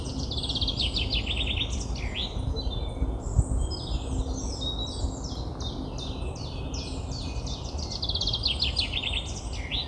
Vogelzang | Houtem Natuur Waarnemingen
Vink-Fringilla-coelebs.mp3